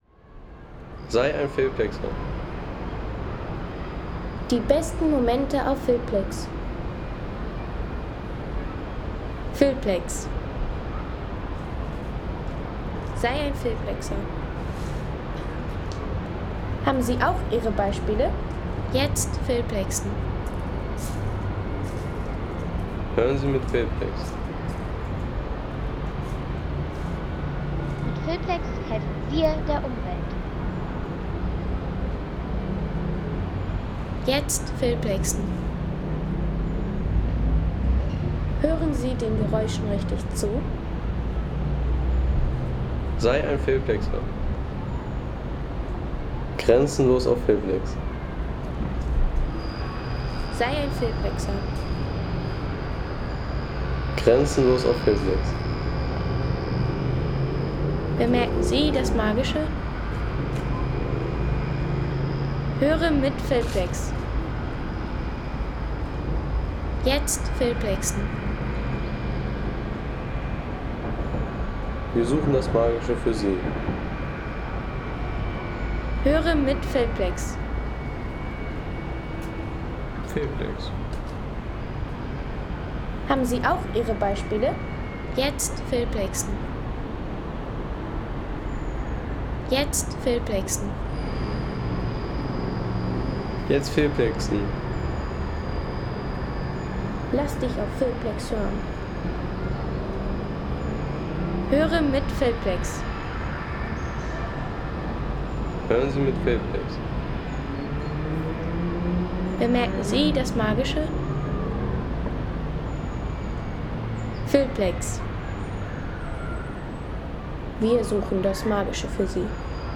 Akustisches Stadtpanorama – Ein Tag am Grazer Schlossberg.